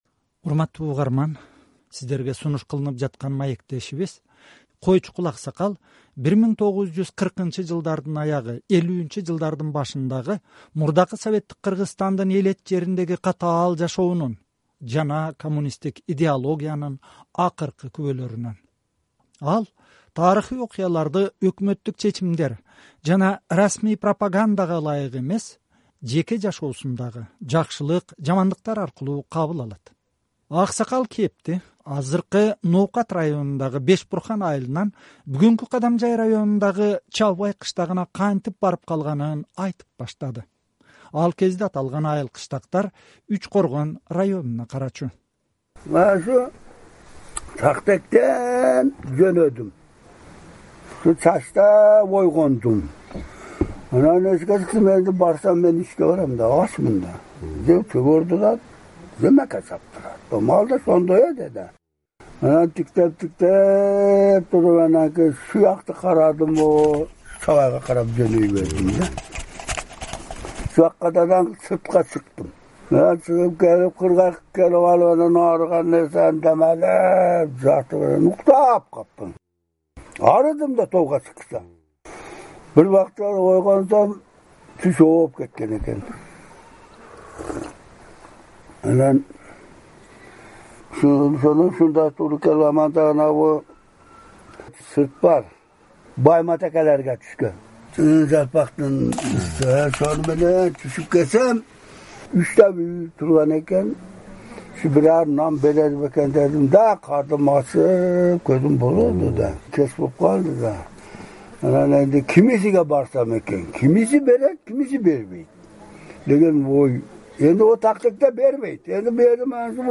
ичкилик говорунда сүйлөйт.